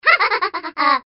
小孩哈哈笑声音效免费音频素材下载